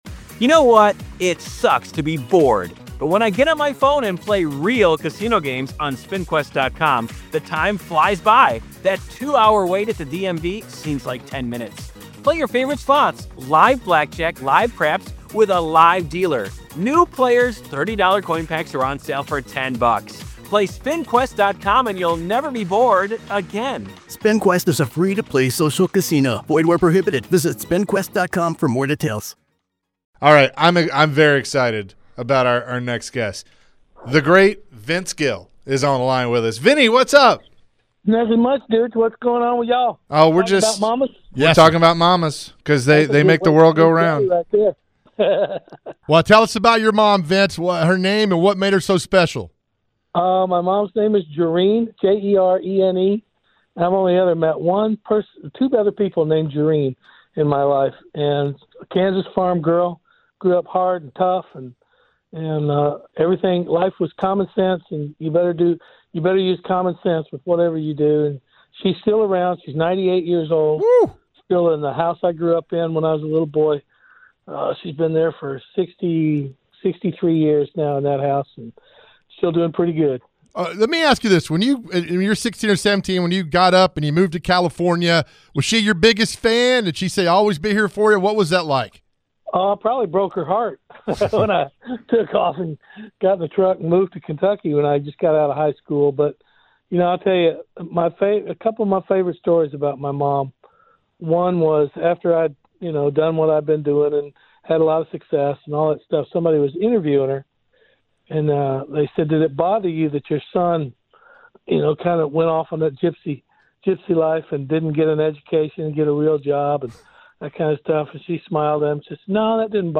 Vince shared his thoughtful memories as a kid of his mom and what makes Mother’s Day so special. Listen to the full conversation.